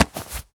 foley_object_grab_pickup_04.wav